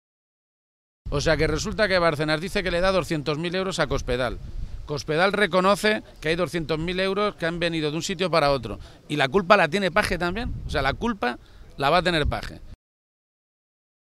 Se pronunciaba de esta manera esta mañana García-Page, en Toledo, a preguntas de los medios de comunicación, y se paraba a analizar lo paradójico de que desde el PP y la televisión de Castilla-La Mancha se le pidan a explicaciones a él sobre esa adjudicación, hecha y formalizada cuando el PP gobernaba el ayuntamiento de Toledo.
Cortes de audio de la rueda de prensa